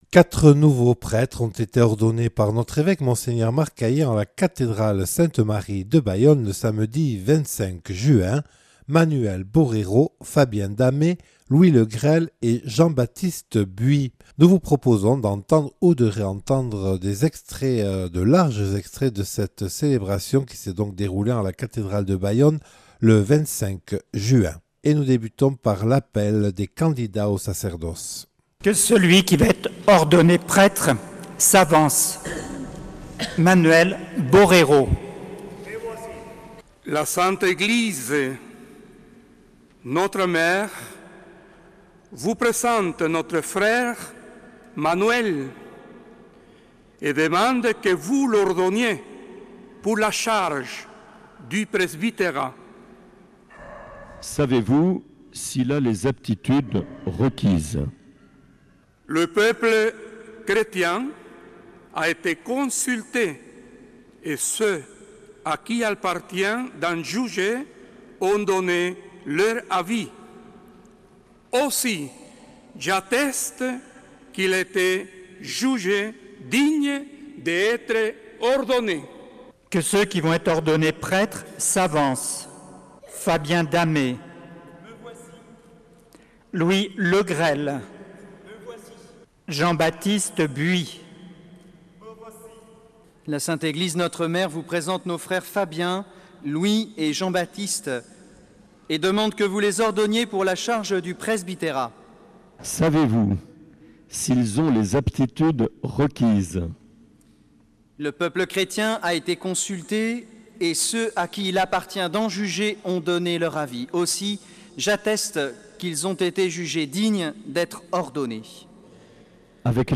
Morceaux choisis des ordinations sacerdotales du 25 juin à la cathédrale de Bayonne